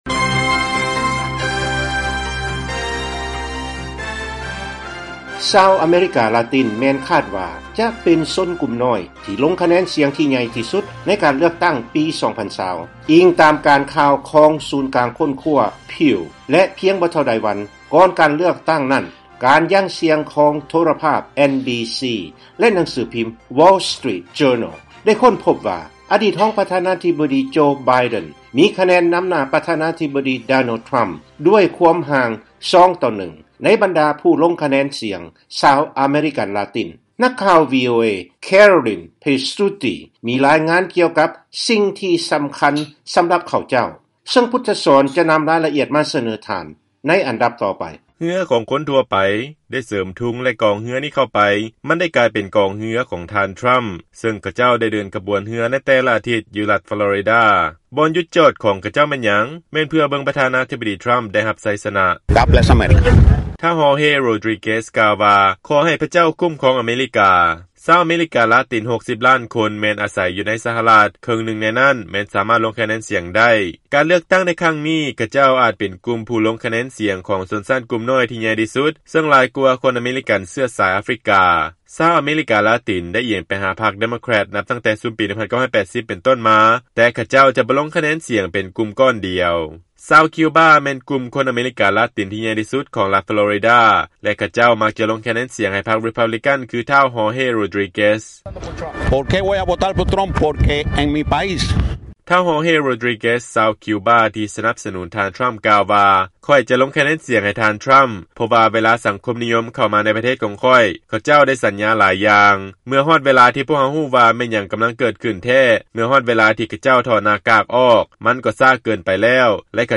ຟັງລາຍງານ ຜູ້ລົງຄະແນນສຽງ ຊາວອາເມຣິກາ ລາຕິນ ອາດໝາຍເຖິງ ຄວາມແຕກຕ່າງ ໃນລັດທີ່ມີການສະໜັບສະໜູນ ສອງພັກການເມືອງເທົ່າກັນ